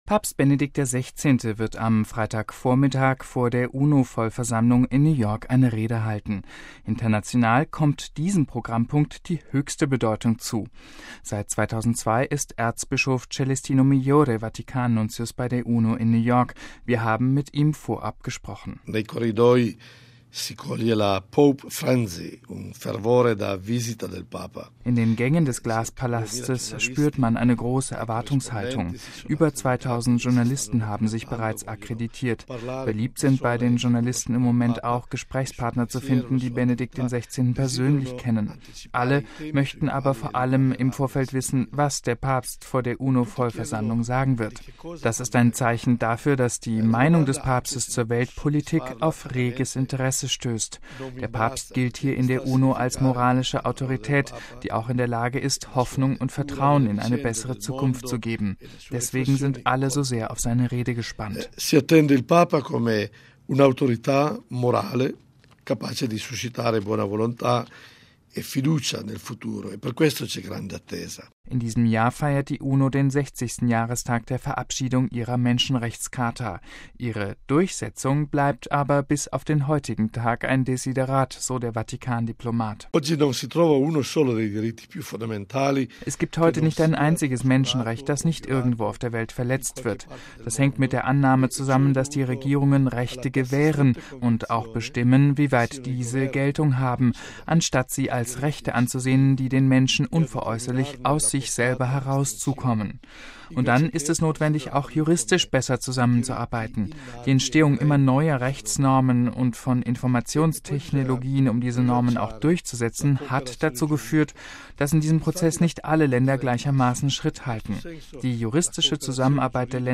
Seit 2002 ist Erzbischof Celestino Migliore Vatikan-Nuntius bei der UNO in New York – wir haben mit ihm vorab gesprochen: